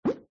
crashwall.mp3